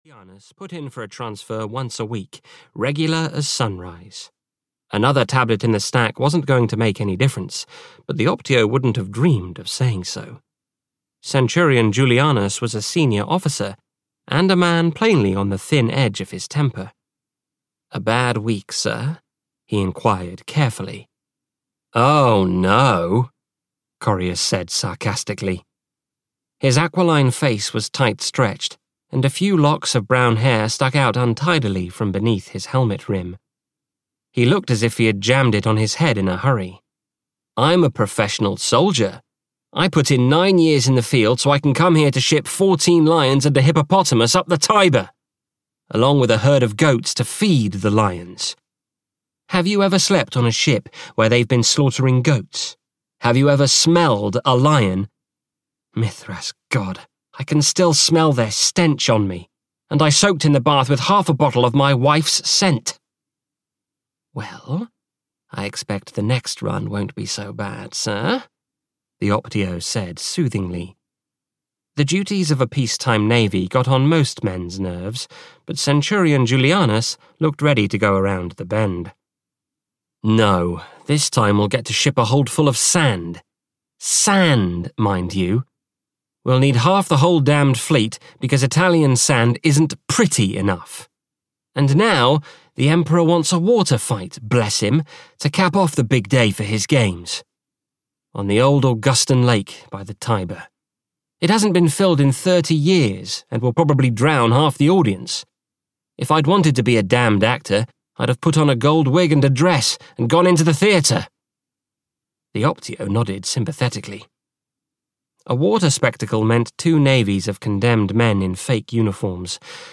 The Emperor's Games (EN) audiokniha
Ukázka z knihy